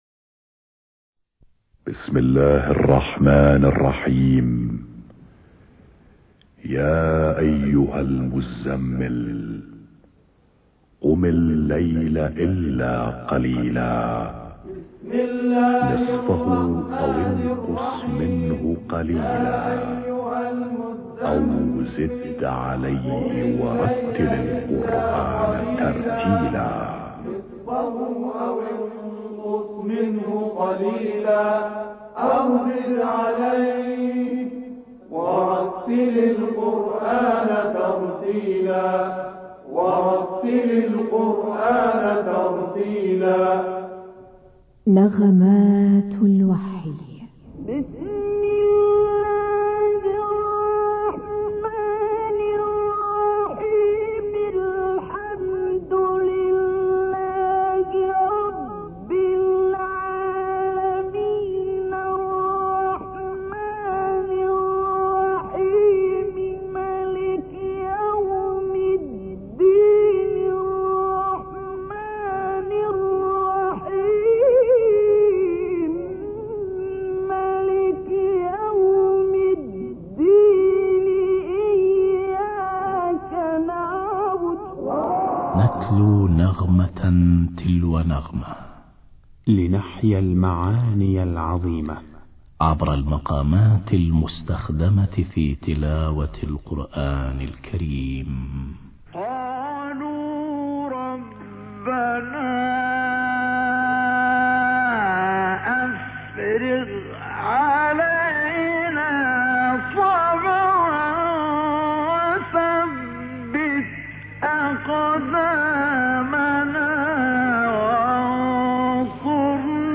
نغمات الوحي- مقام النهاوند